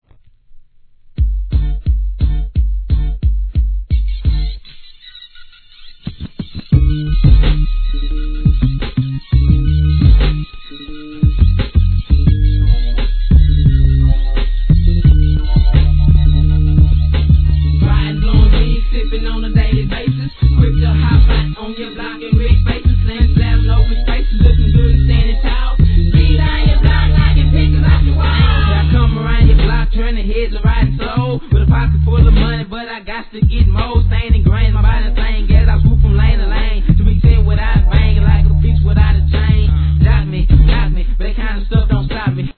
G-RAP/WEST COAST/SOUTH
忍者君（←ファミコンの）ぽい上音がたまらんです!